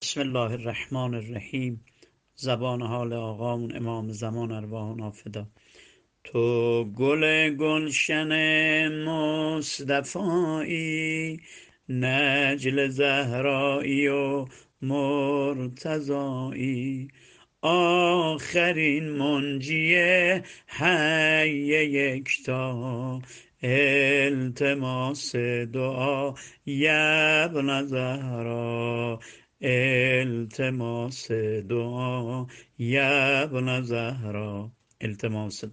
سبک زمزمه